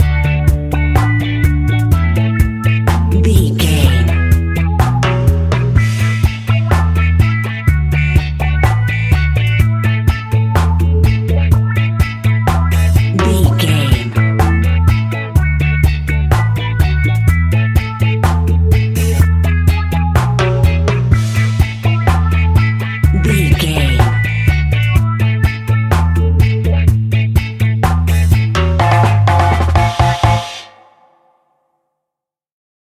Classic reggae music with that skank bounce reggae feeling.
Uplifting
Ionian/Major
dub
instrumentals
laid back
chilled
off beat
drums
skank guitar
hammond organ
percussion
horns